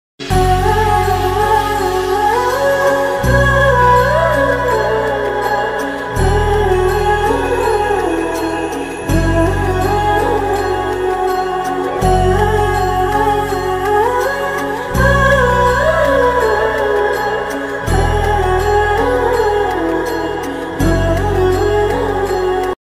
Dj Song Ringtone.